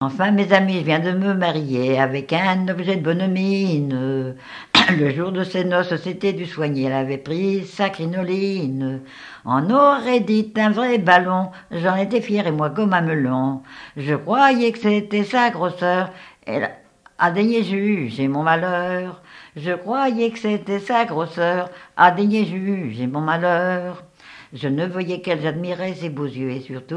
Genre strophique
témoignage sur les noces et chansons traditionnelles
Pièce musicale inédite